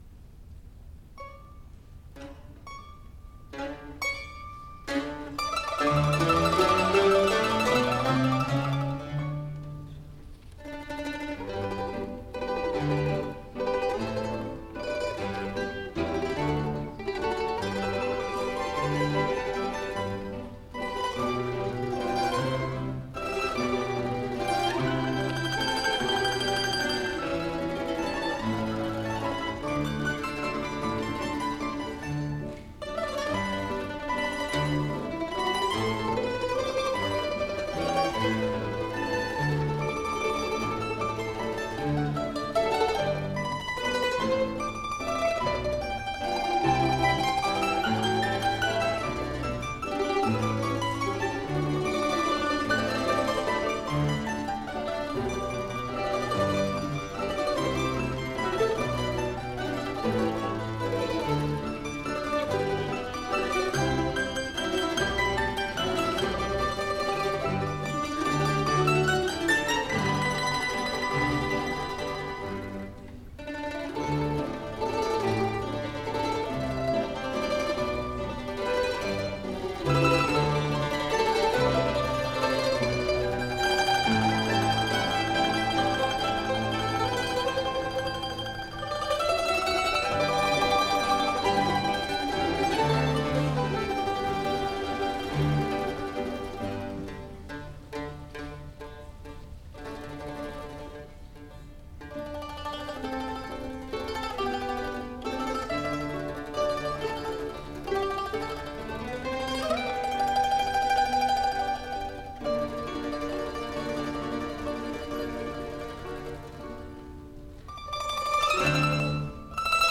ソロとアンサンブルの夕べ(1980.7.2 府立文化芸術会館)
マンドリン合奏(中野二郎作品集)